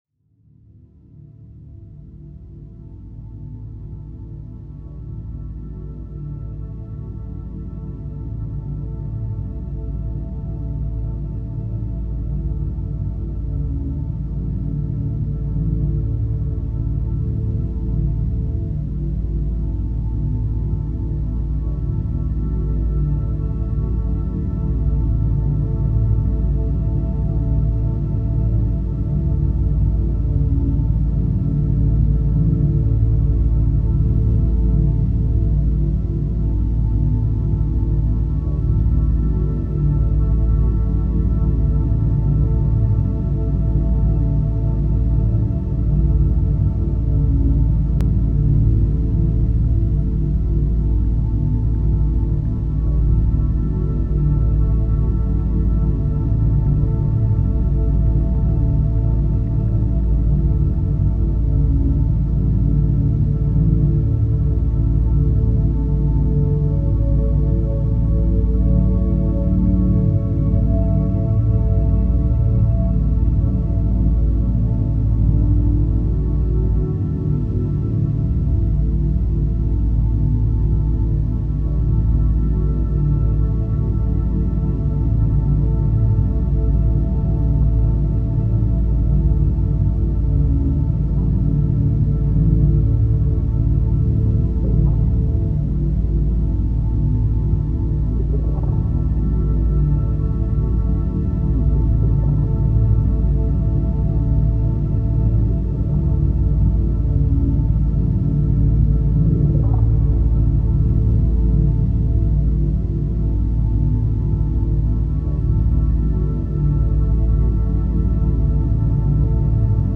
ambient style
His projects concerns experimental music, Idm and Ambient.